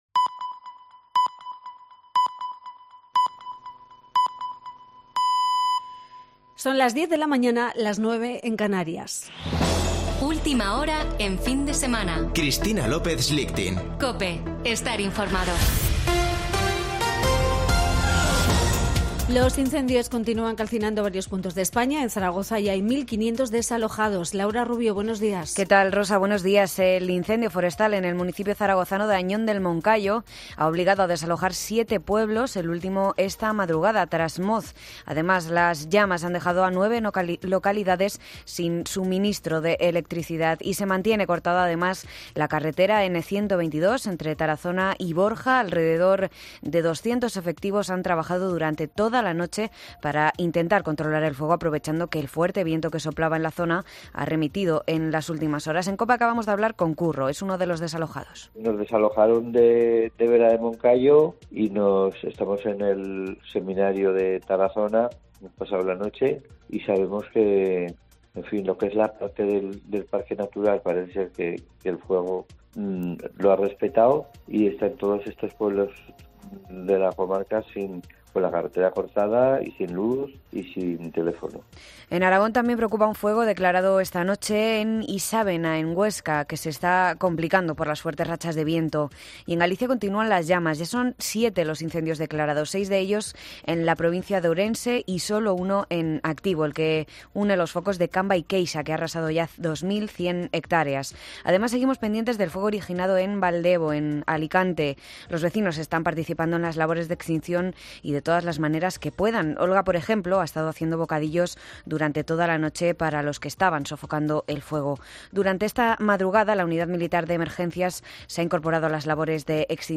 Boletín de noticias de COPE del 14 de agosto de 2022 a las 10.00 horas